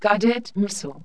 GMISSILE.WAV